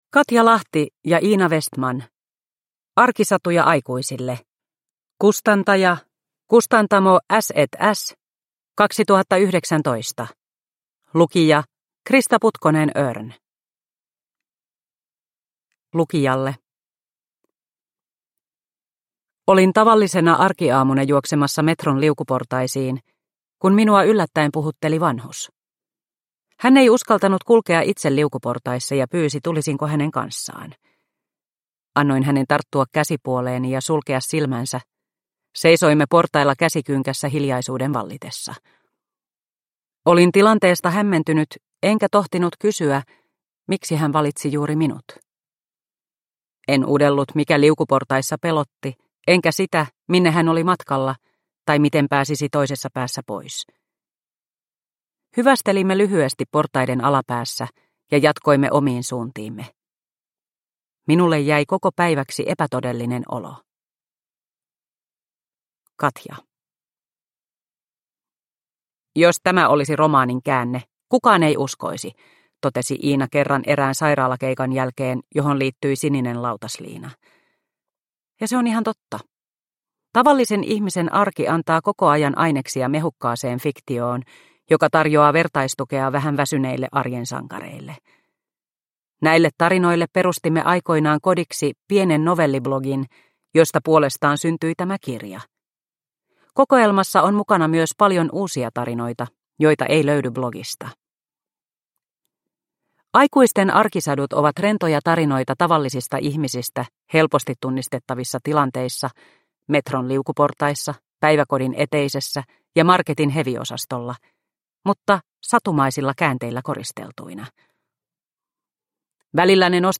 Arkisatuja aikuisille – Ljudbok – Laddas ner